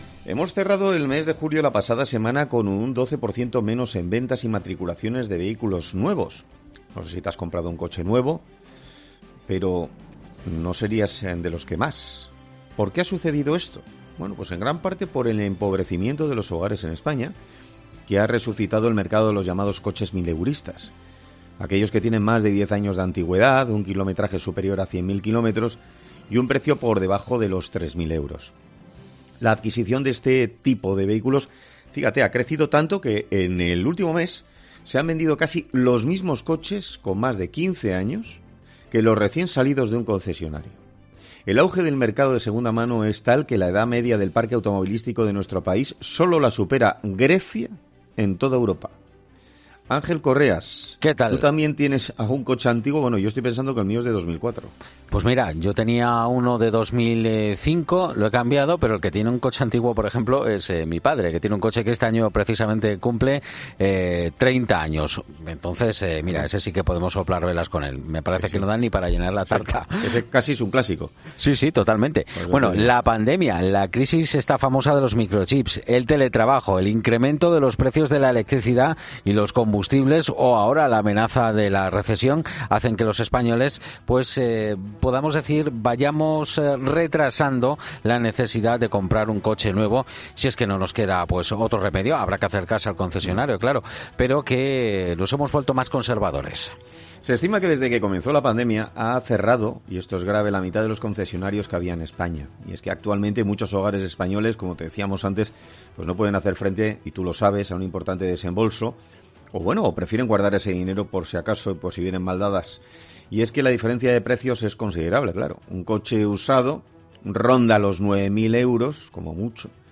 En 'Herrera en COPE' han hablado el dueño de un concesionario de coches de segunda mano y con el comprador de uno de estos coches para entender más...